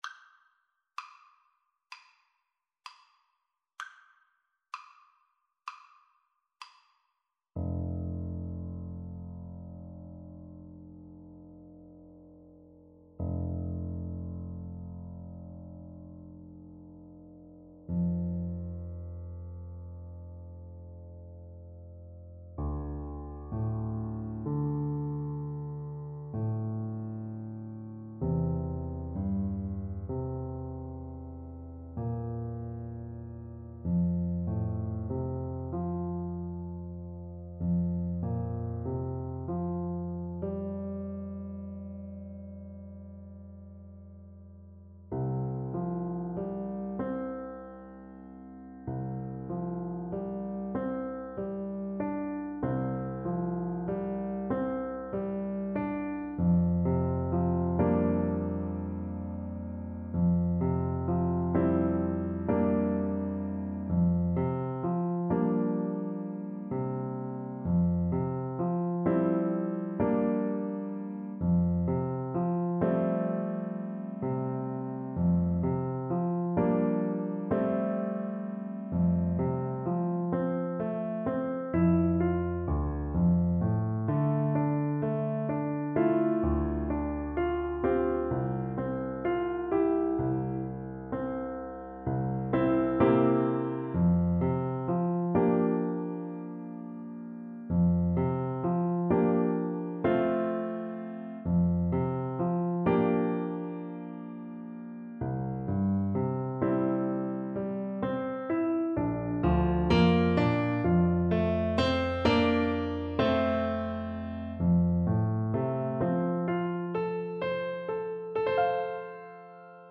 4/4 (View more 4/4 Music)
Andante cantabile = c. 90